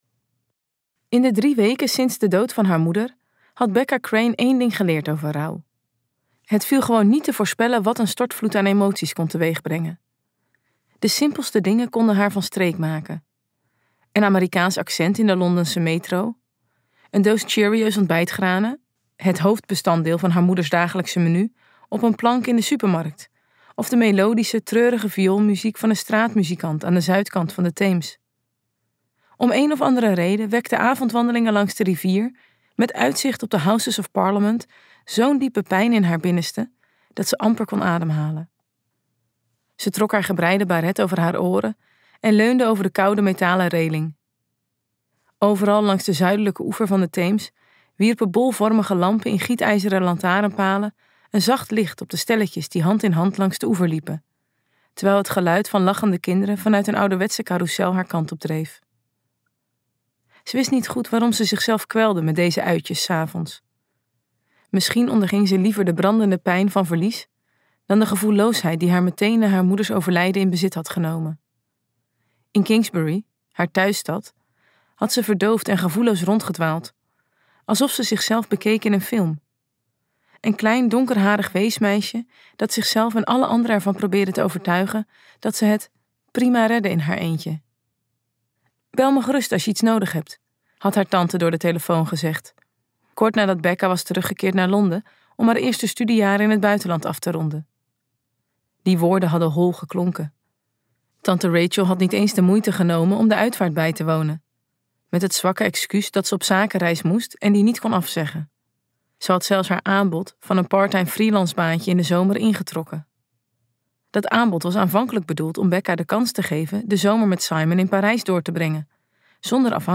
KokBoekencentrum | Stromen van water luisterboek